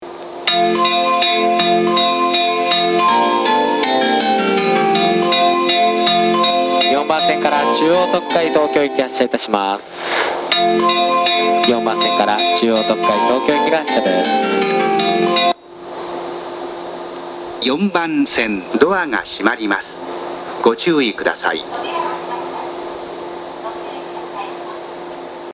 発車メロディー
1.6ターン（0.8コーラスです）。
快速は信号開通が遅く、鳴っても1ターンが多いです。